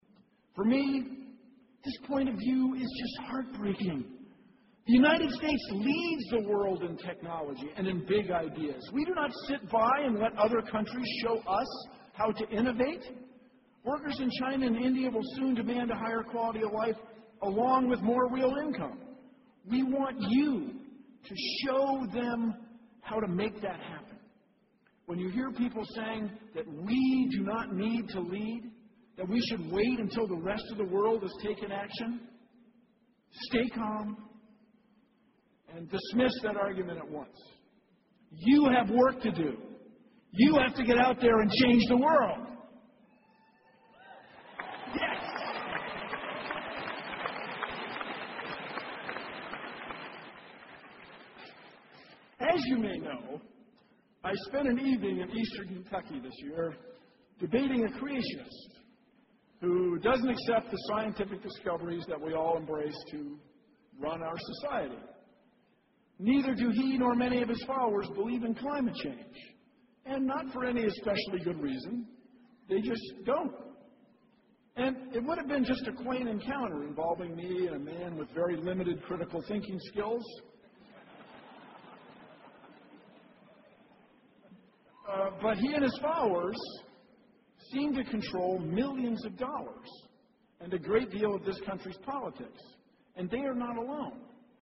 公众人物毕业演讲 第163期:比尔·奈马萨诸塞大学2014(10) 听力文件下载—在线英语听力室